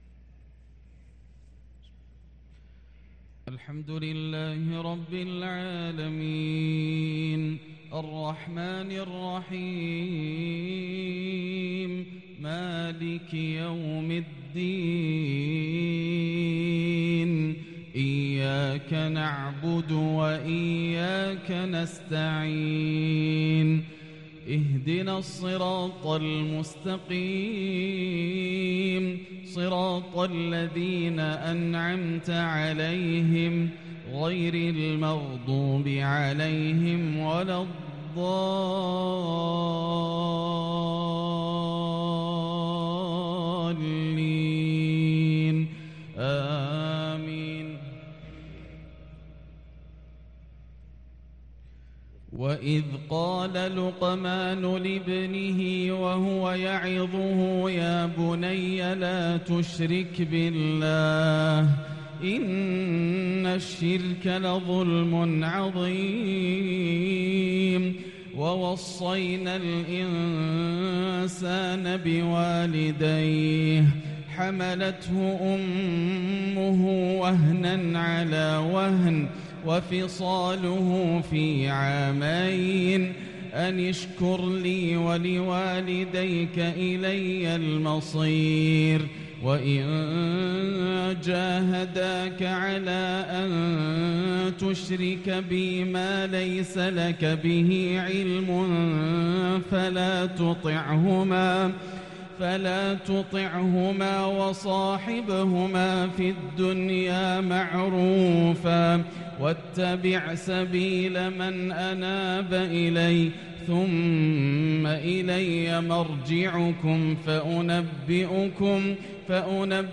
عشاء الجمعة 6-2-1444هـ من سورة لقمان |Isha prayer from surat Luqman 2-9-2022 > 1444 🕋 > الفروض - تلاوات الحرمين